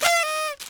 partyHorn.wav